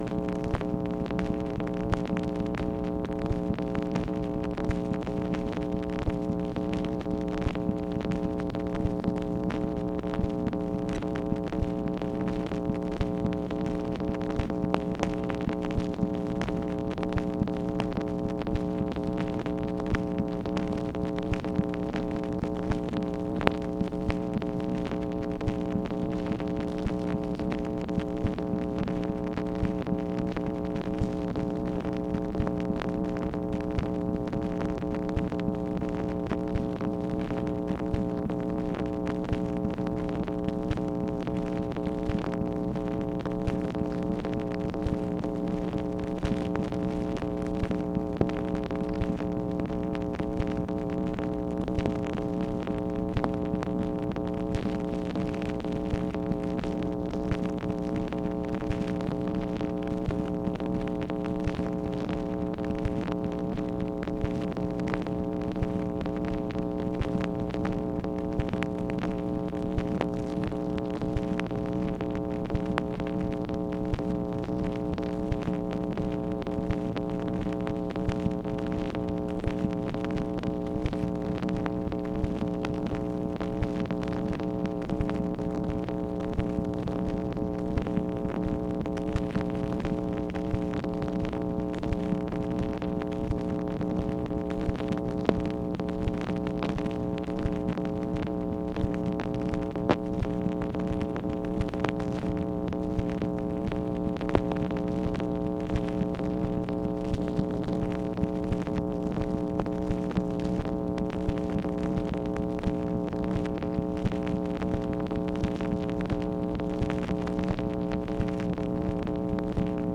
MACHINE NOISE, May 18, 1965
Secret White House Tapes | Lyndon B. Johnson Presidency